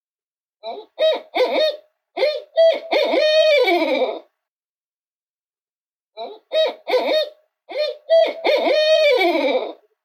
Their most distinctive call however, is their loudest, it sounds like "who cooks for you? Who cooks for you-all?"
Barred Owl Call (NTR)